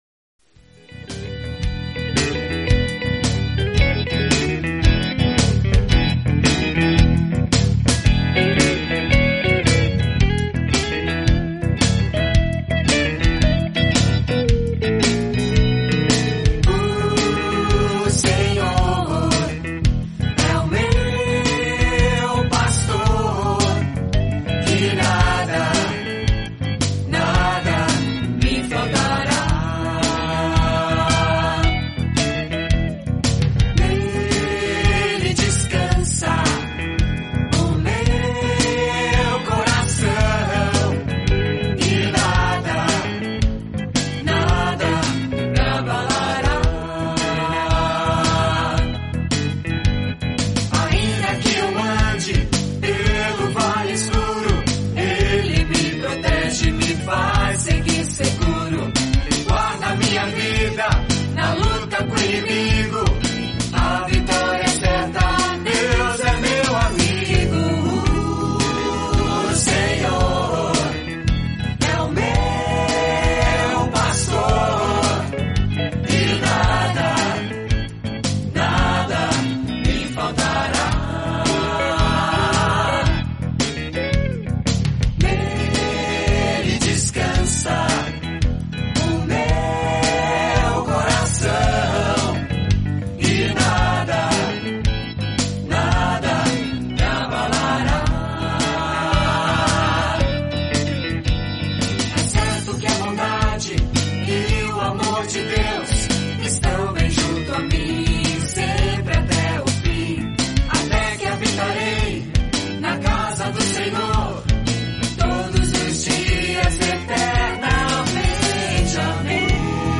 Ocorreu, no entanto, que nesta última semana estive, agora na Rádio Zé FM, para participar do programa Mensagem de Paz, das Igrejas Presbiterianas de Americana.